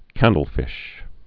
(kăndl-fĭsh)